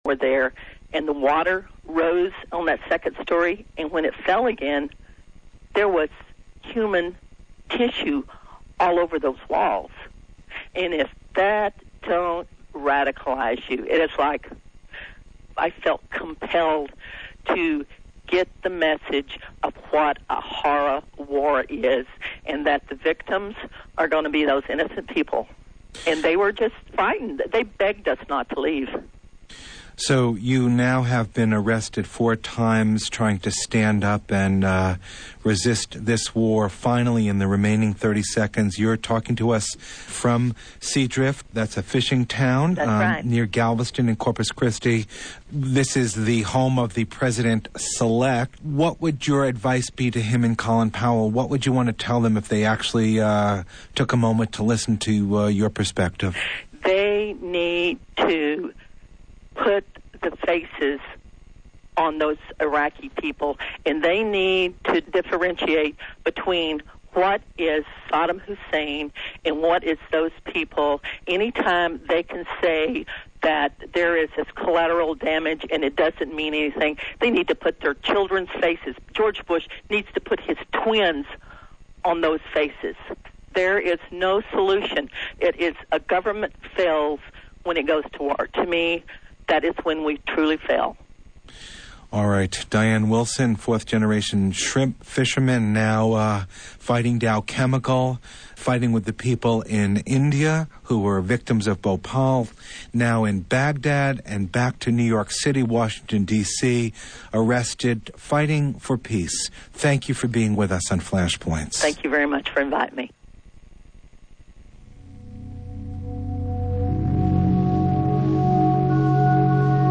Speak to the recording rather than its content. LIVE AUDIO REPORTS